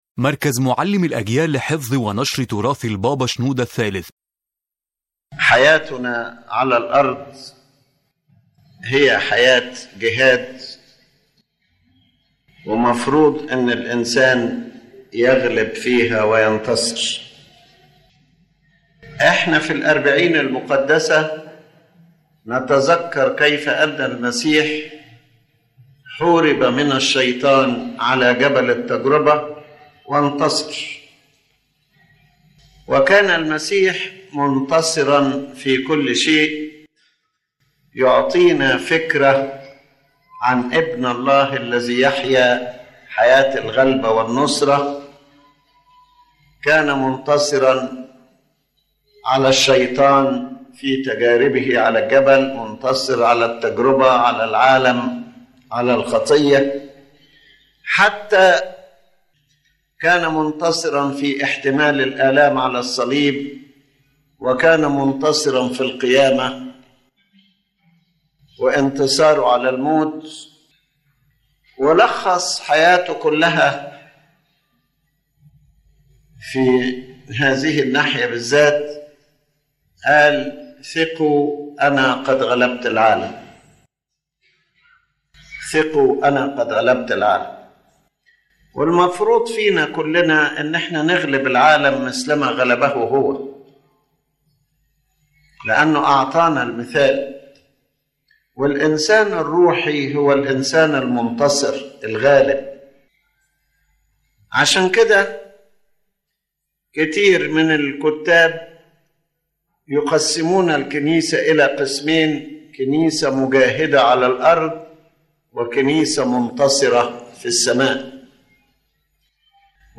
His Holiness Pope Shenouda III explains in this lecture that life on earth is a life of struggle and a spirit of confrontation, and the believer is supposed to live as a victor and overcomer as Christ overcame the world. The lecture links Christ’s experience and the experiences of the holy fathers with the call for the believer to personal and spiritual victory.